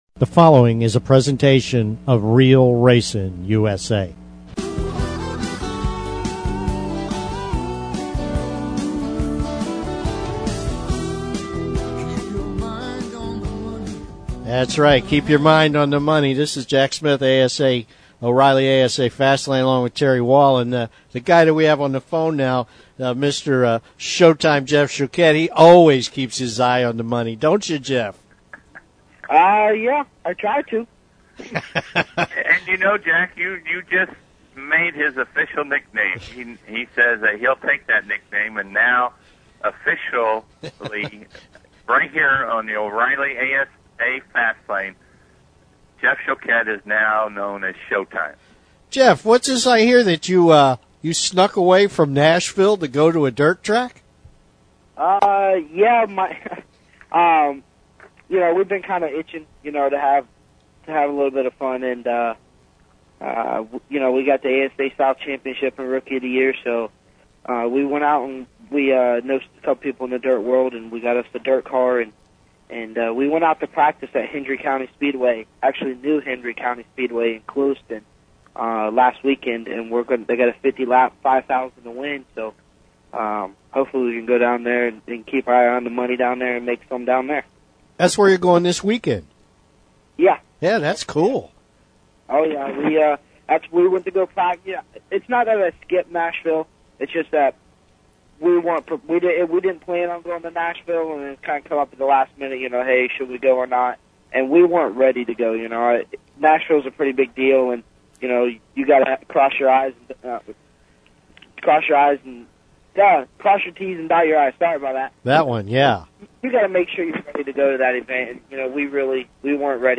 "Inside Florida Racing" All shows are archived for you to hear anytime. IFR is a positive show with news from around Florida racing and interviews with the promoters, drivers, fans and others who make up the Florida stock car racing community.